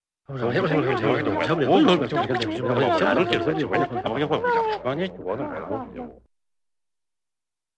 Звуки бормотания
Люди переговариваются на непонятном языке